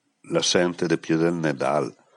10 frasi in dialetto su Santa Lucia